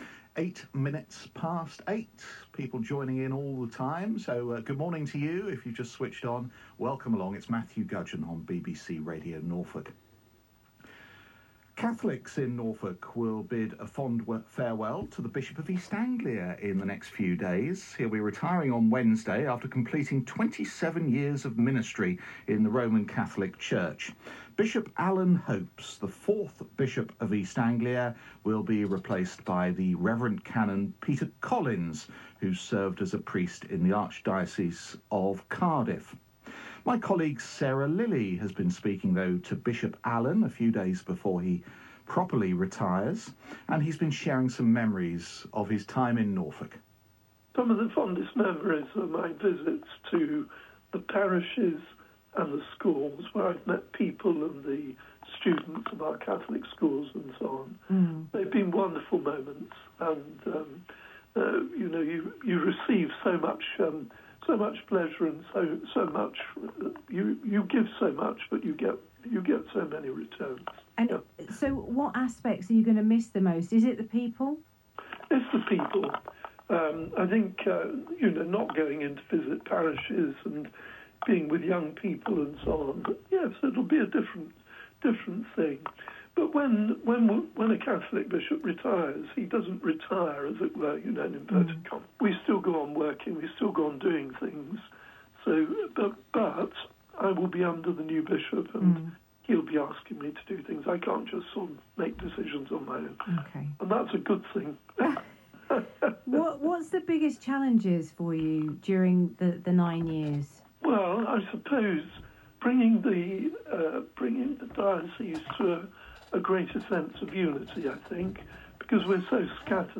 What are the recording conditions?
BishopAlanRetiresBBCRadioNorfolkDec22.mp3